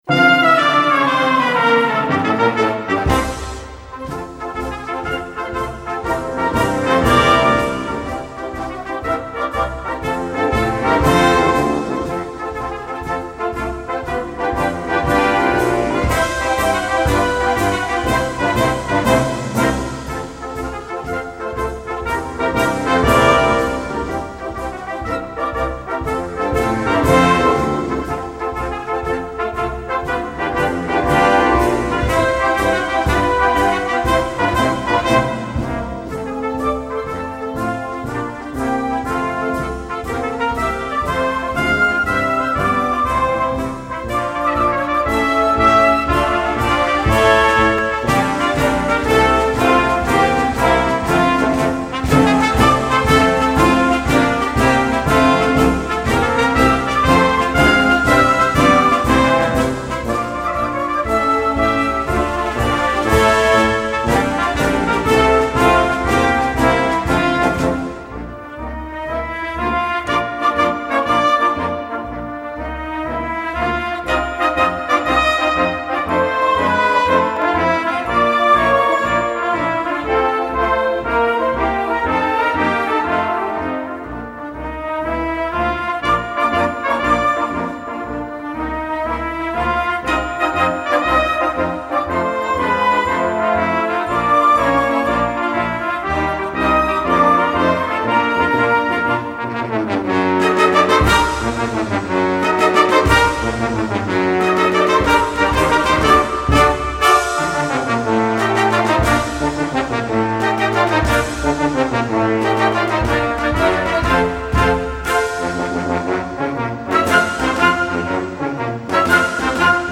marches